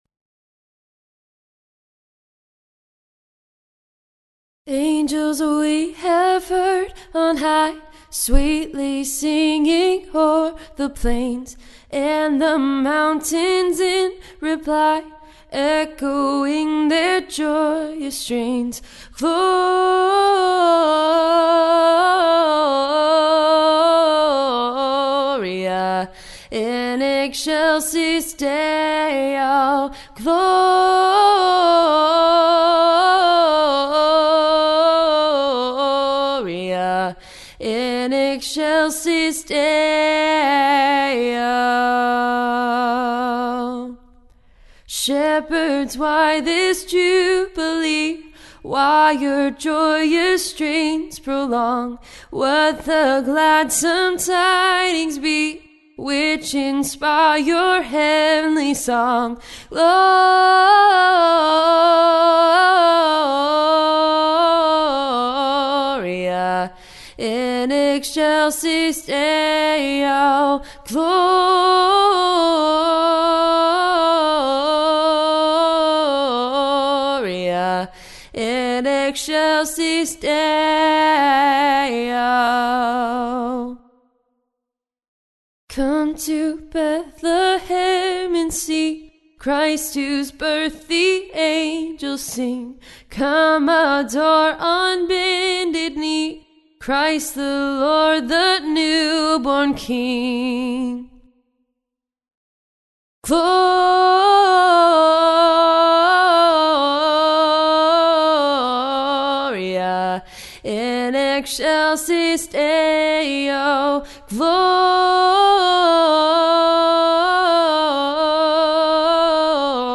Woman sings solo without music in English a cappella